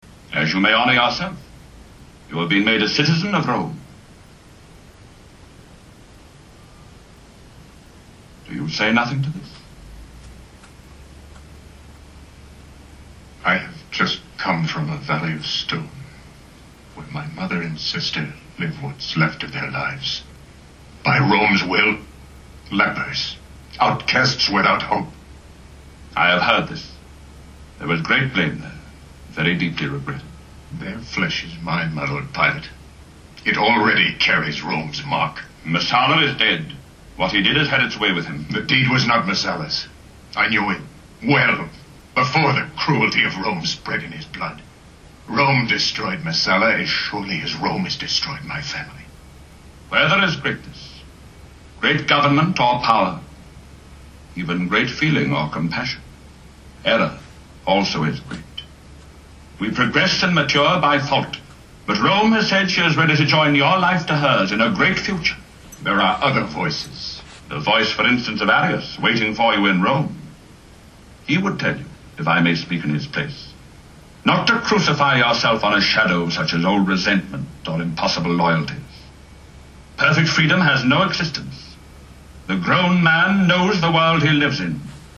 The grown man knows the world he lives in.” — written by Gore Vidal and spoken by Frank Thring (as Judea governor Pontius Pilate) in Act 3 of William Wyler‘s Ben-Hur.